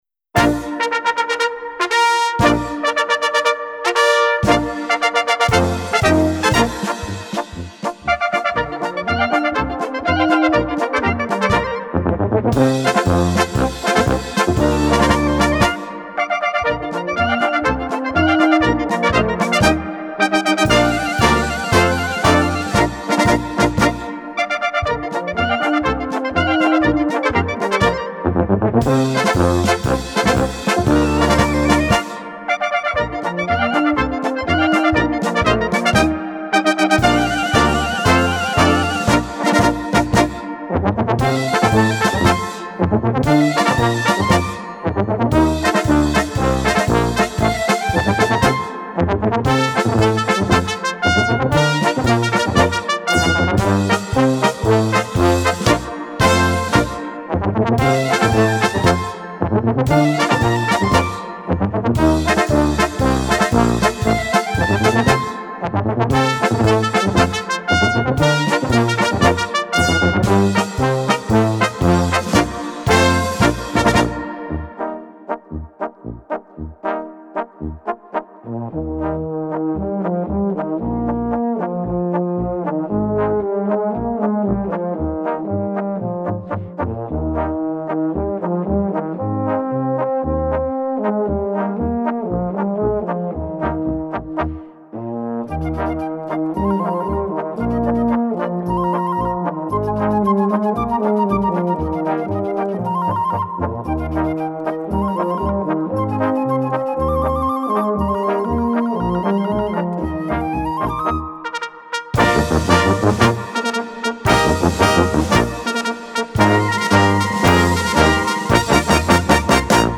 Gattung: March Besetzung: Blasorchester PDF